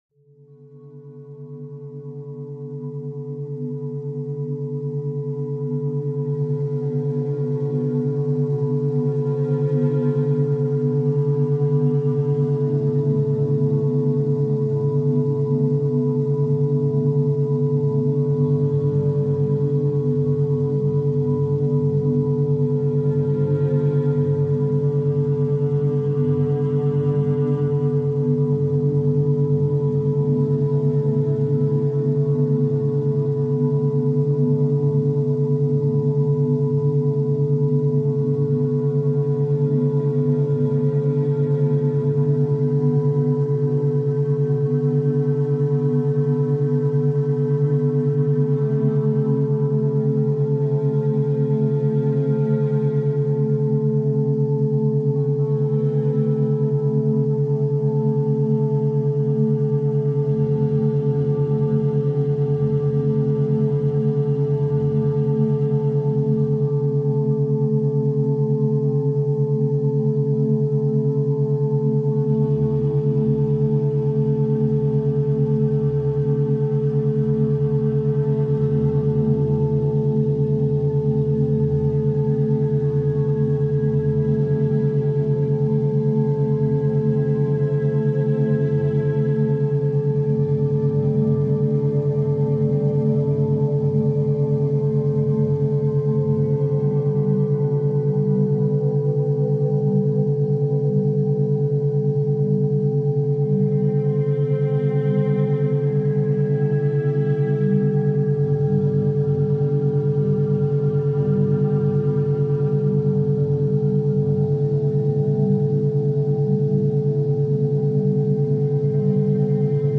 Étude forestière naturelle · méthode scientifique calme 2 heures